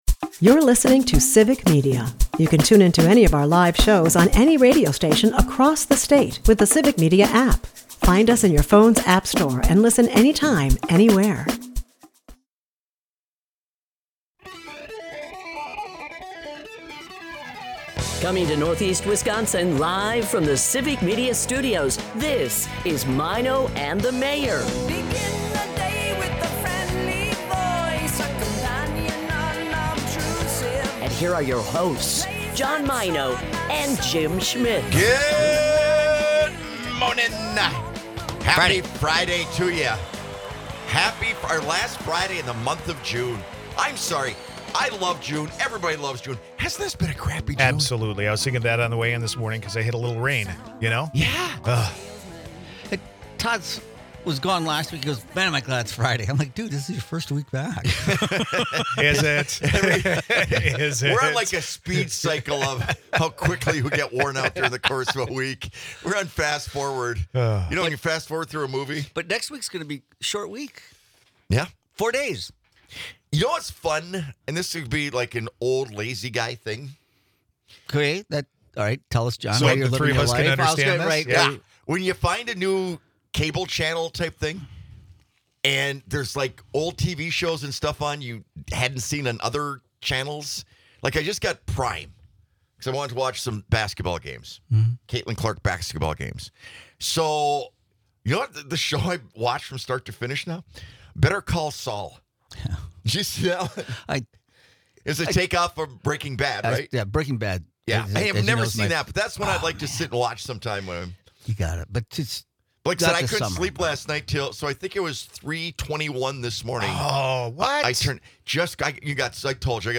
Food, weather, and tech intersect in this fast-paced, humorous conversation.